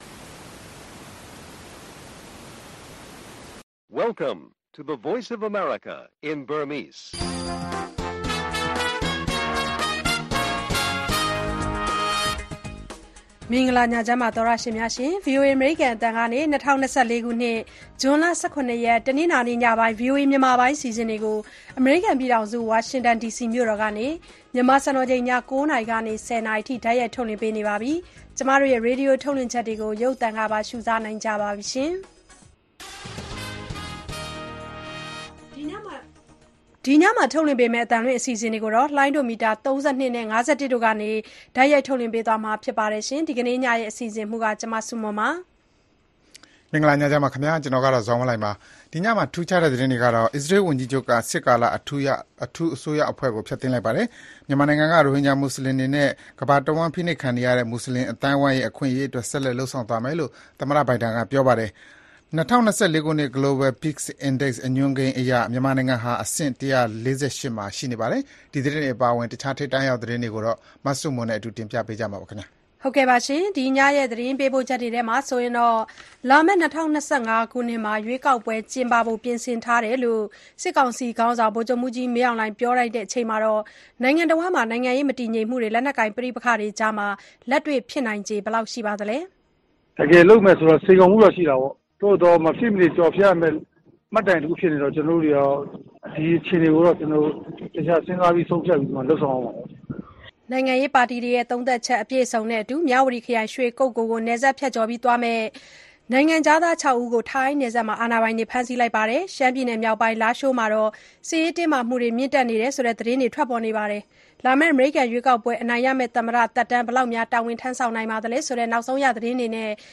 ရိုဟင်ဂျာတွေ အခွင့်အရေး အမေရိကန် ဆက်လက်အားပေးသွားဖို့ သမ္မတဘိုင်ဒင် ကတိပြု၊ စစ်ကောင်စီရဲ့ ရွေးကောက်ပွဲ အစီအစဉ်အပေါ် နိုင်ငံရေးပါတီတချို့အမြင်၊ စီးပွားရေးပညာရှင် ပါမောက္ခ Sean Turnell နဲ့ မေးမြန်းခန်း စတာတွေအပြင် စီးပွားရေး၊ လူမှုရှုခင်း သီတင်းပတ်စဉ် အစီအစဉ်တွေကို တင်ဆက်ထားပါတယ်။